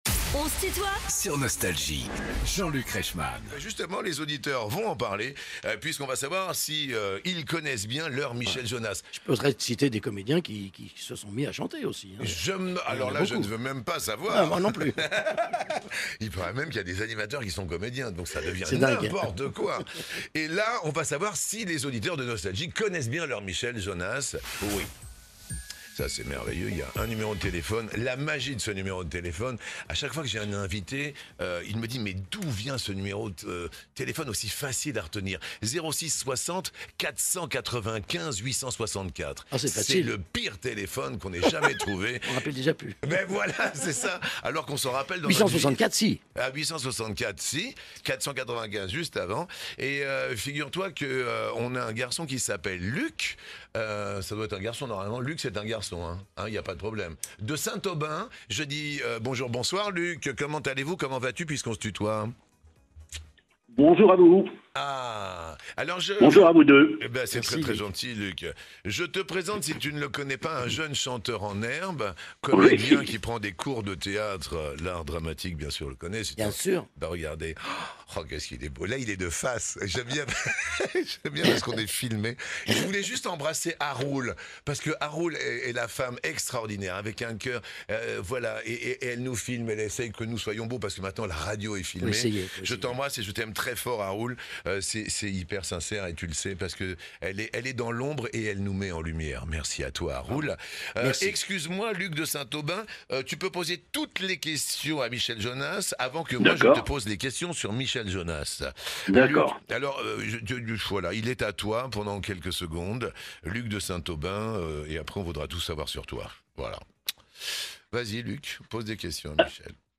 Michel Jonasz est l'invité de "On se tutoie ?..." avec Jean-Luc Reichmann
Erweiterte Suche Tu connais bien ton Michel Jonasz ? 11 Minuten 10.31 MB Podcast Podcaster Les interviews Les plus grands artistes sont en interview sur Nostalgie.